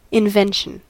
Ääntäminen
IPA : /ɪnˈvɛnʃən/